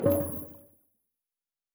Fantasy Interface Sounds
Special Click 10.wav